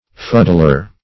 fuddler - definition of fuddler - synonyms, pronunciation, spelling from Free Dictionary Search Result for " fuddler" : The Collaborative International Dictionary of English v.0.48: Fuddler \Fud"dler\, n. A drunkard.